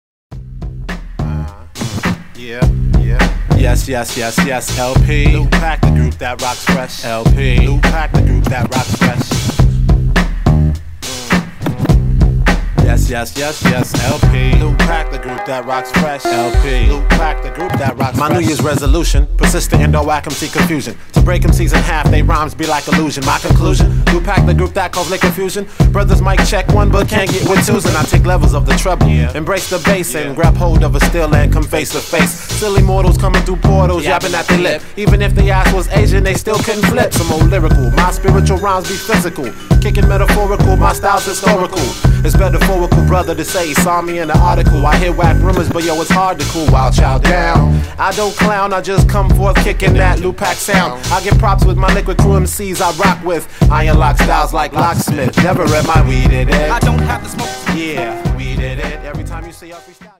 New & 2nd hand hip hop, funk, soul, reggae, jazz & more…